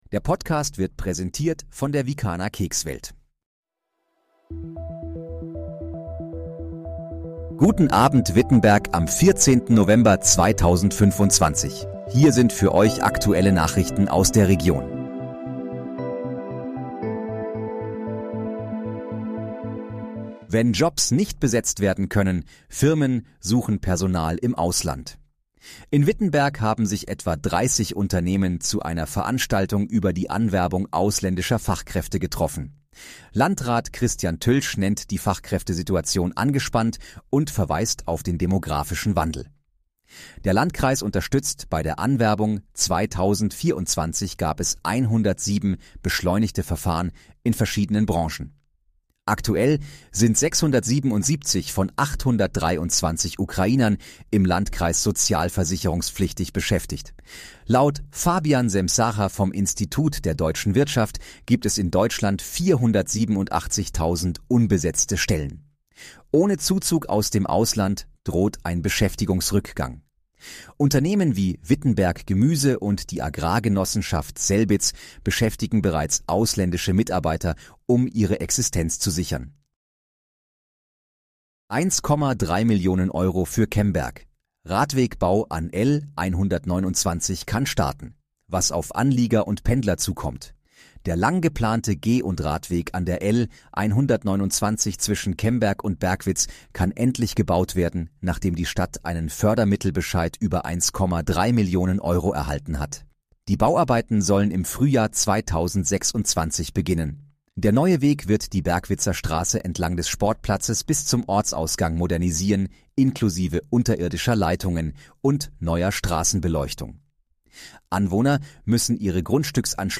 Guten Abend, Wittenberg: Aktuelle Nachrichten vom 14.11.2025, erstellt mit KI-Unterstützung
Nachrichten